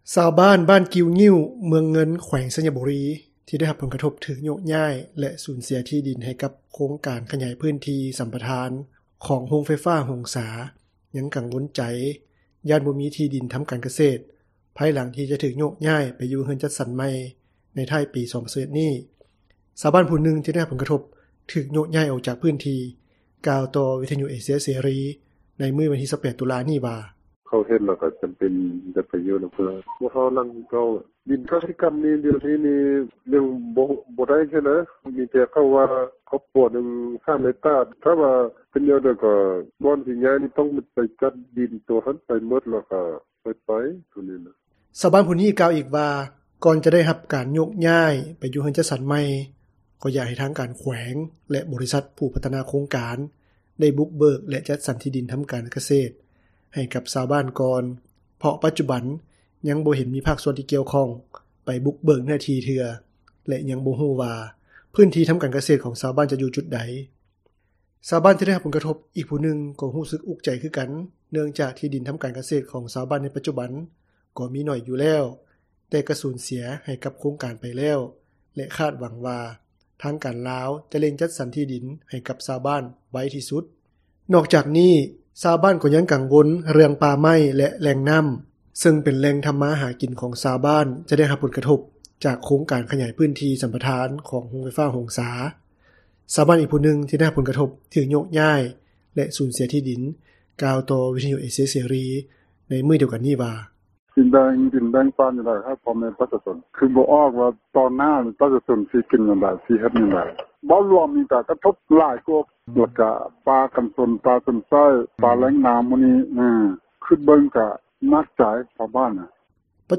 ຊາວບ້ານຜູ້ນຶ່ງ ທີ່ໄດ້ຮັບຜົລກະທົບ ຖືກໂຍກຍ້າຍ ອອກຈາກພື້ນທີ່ ກ່າວຕໍ່ວິທຍຸເອເຊັຽເສຣີ ໃນມື້ວັນທີ 28 ຕຸລາ ນີ້ວ່າ: